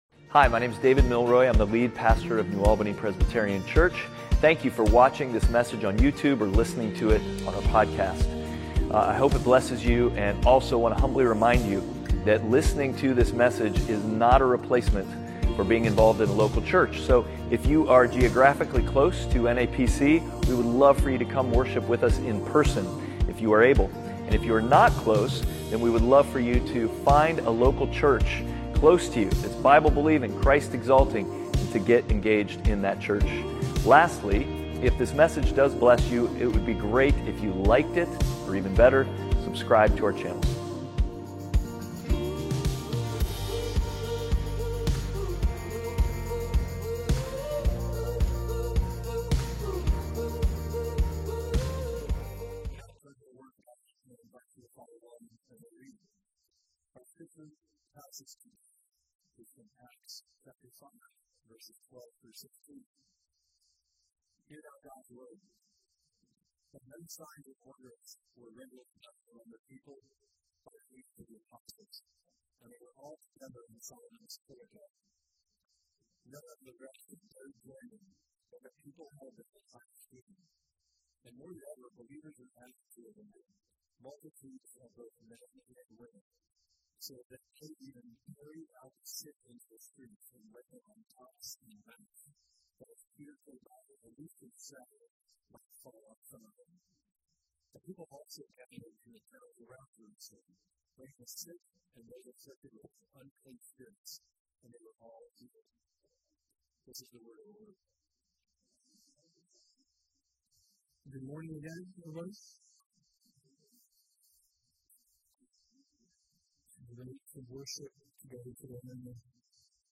Acts 5:12-16 Service Type: Sunday Worship « Outward